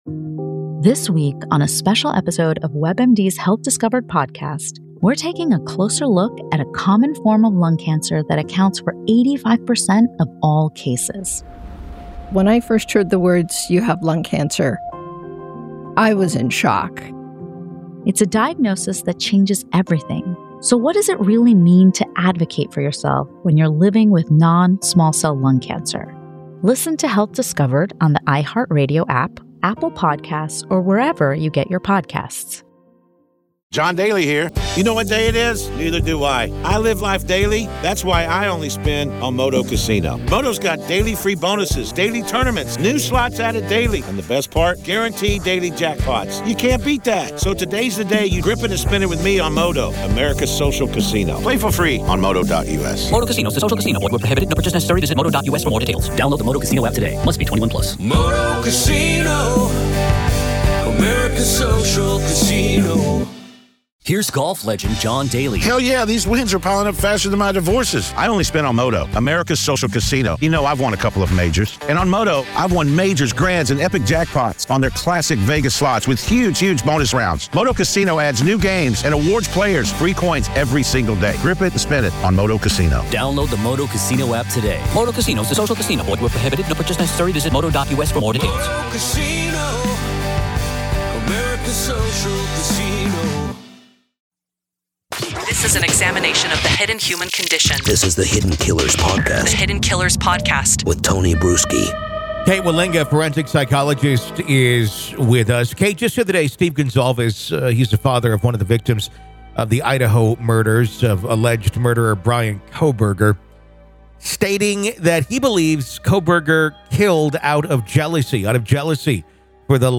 Tune in for a riveting discussion about empathy, morality, and how they intersect with criminal behavior.